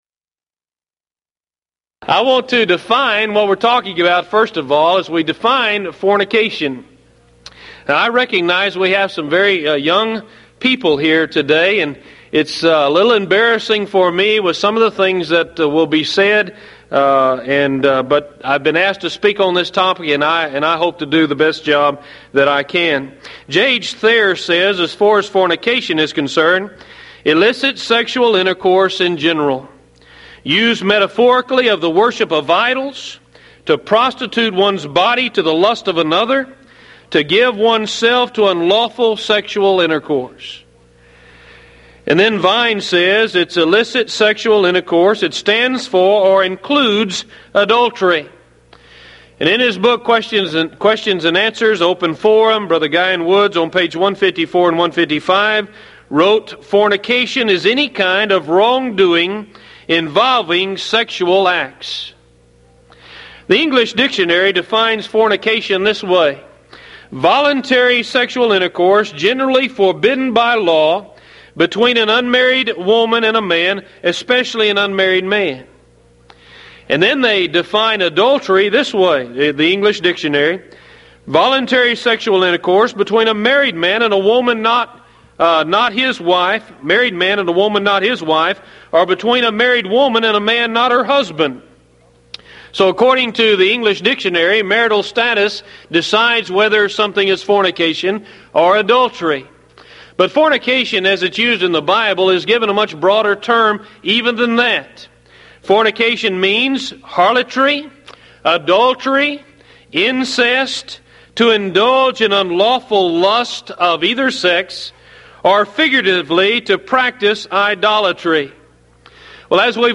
Event: 1996 Mid-West Lectures
lecture